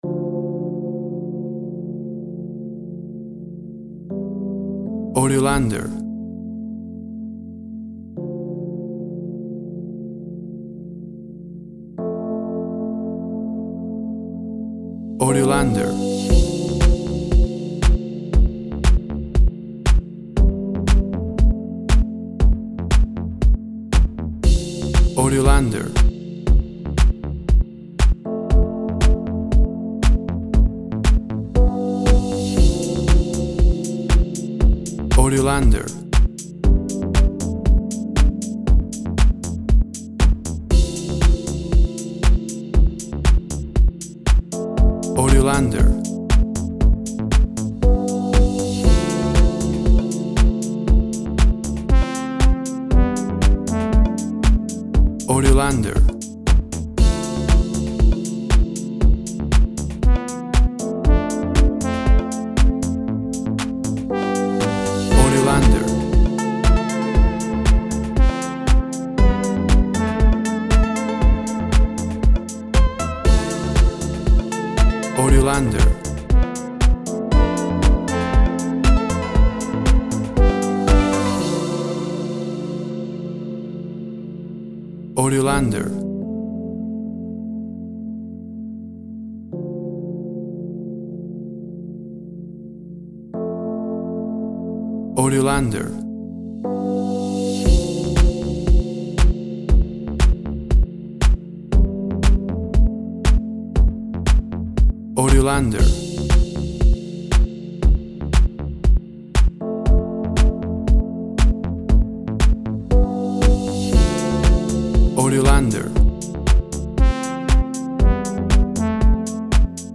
WAV Sample Rate 16-Bit Stereo, 44.1 kHz
Tempo (BPM) 117